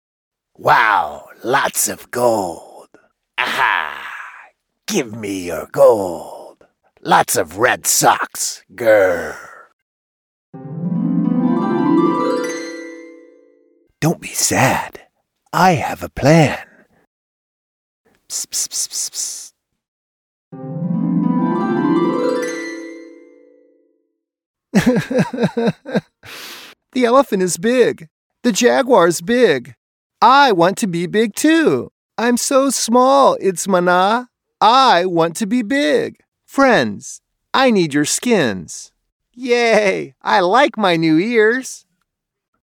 • For voiceovers – a home studio with acoustic treatment, CM25 MkIII condenser microphone, Focusrite Scarlett Si2 interface, Adobe Audition Software.
Character Voices
Character-Voices.mp3